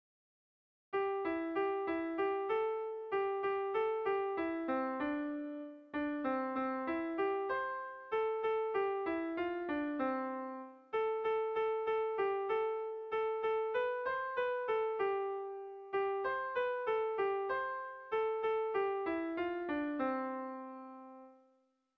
Gabonetakoa
Zortziko txikia (hg) / Lau puntuko txikia (ip)
ABDB2